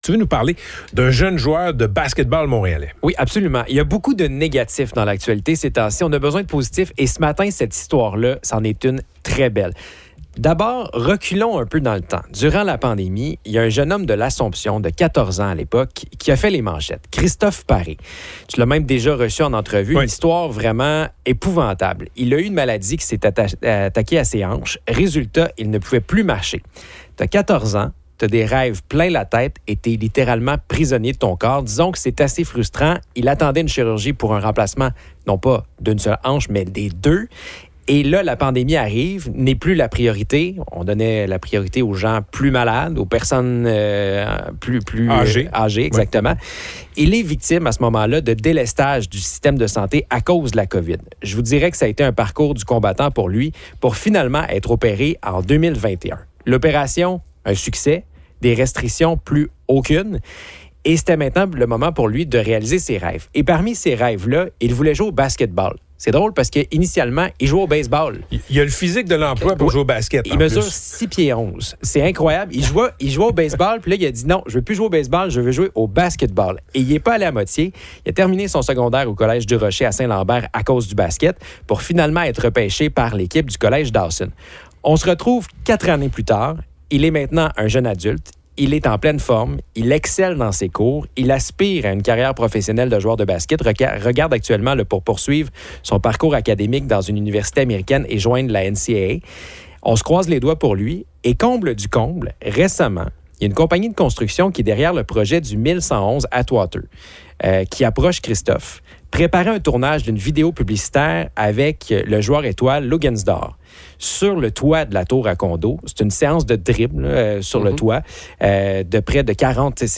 extrait-radio.mp3